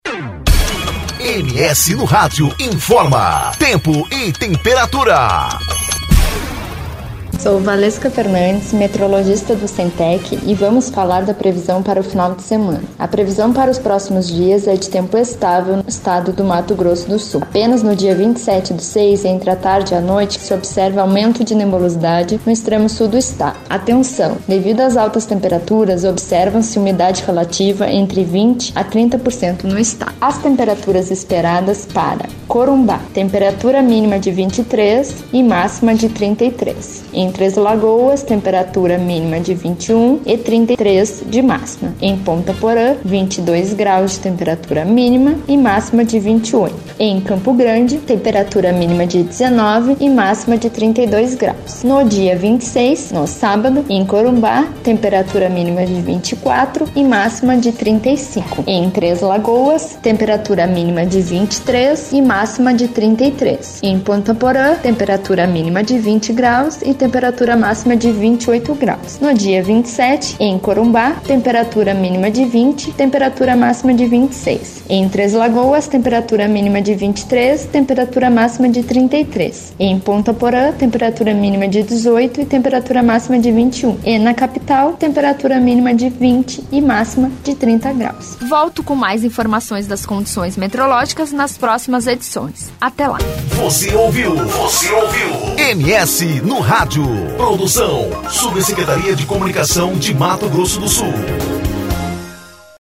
Previsão do Tempo: Tempo estável e baixa umidade relativa do ar